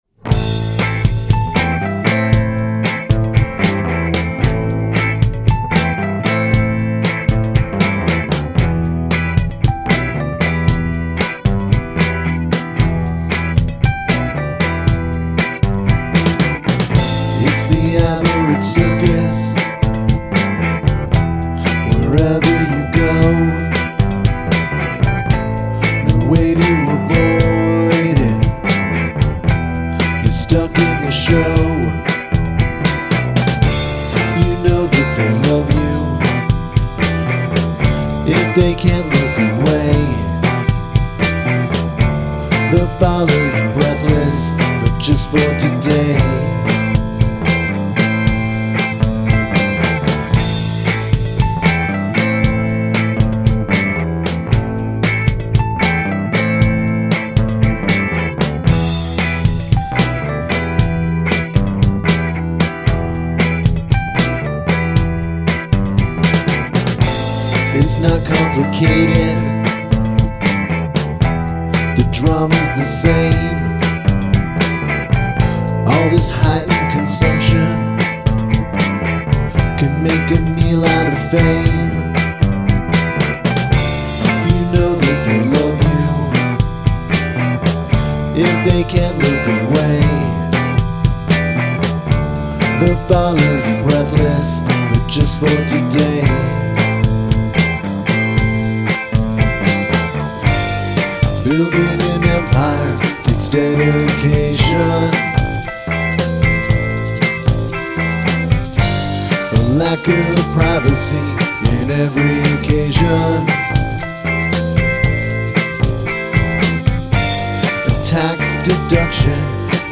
Use something from your garbage bin as an instrument
Chord changes feel too formulaic.